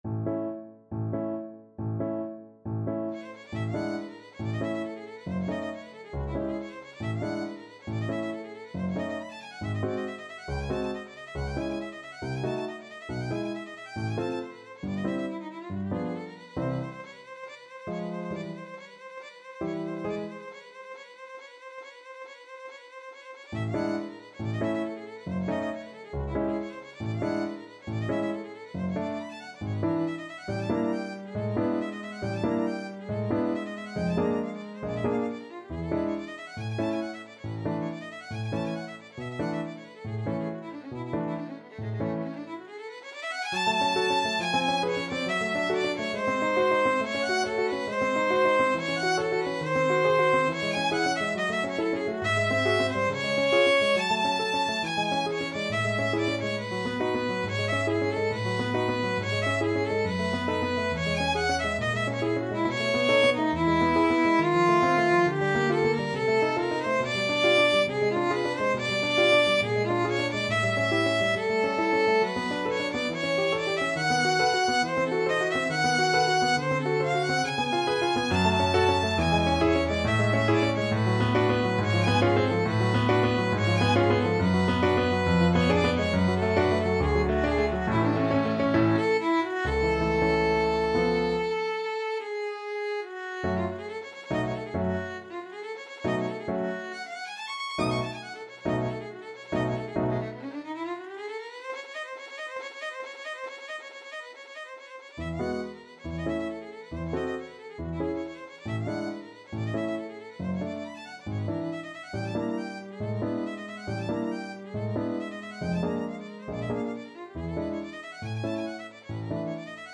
2/4 (View more 2/4 Music)
Allegro vivo =138 (View more music marked Allegro)
Ab4-E7
Classical (View more Classical Violin Music)